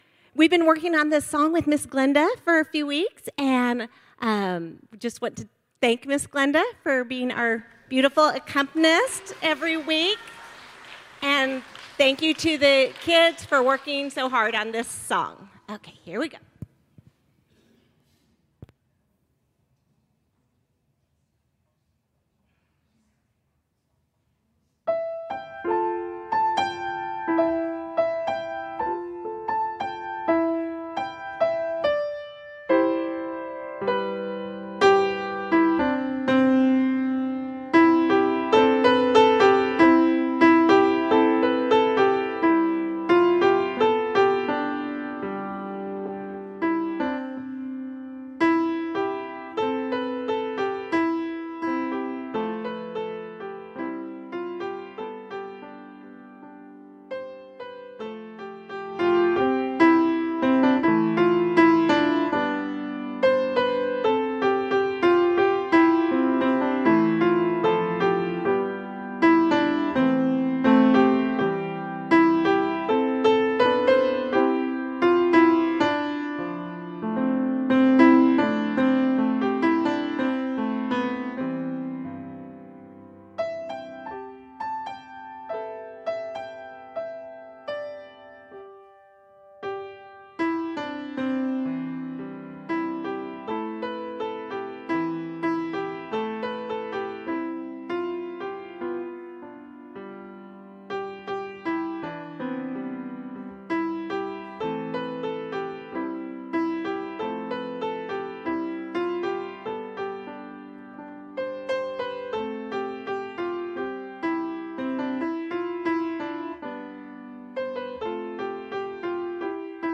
Children’s Time